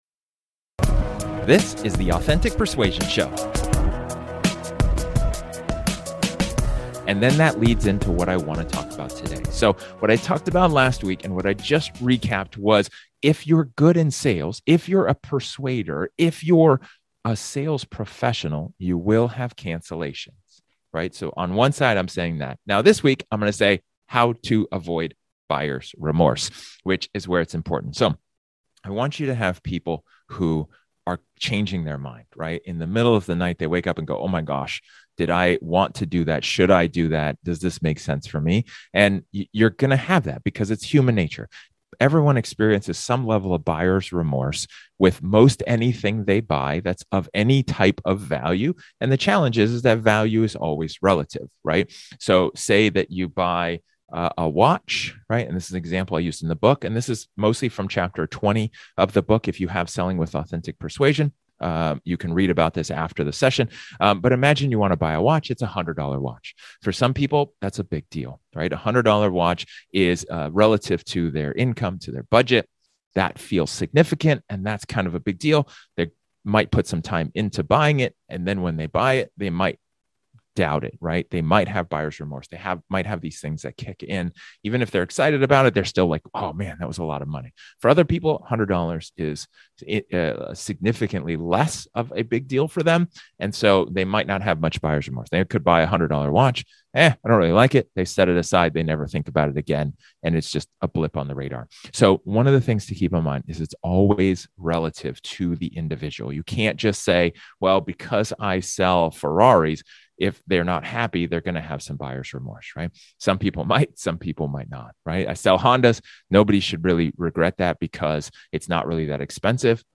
In this solo episode, my topic is all about buyer's remorse.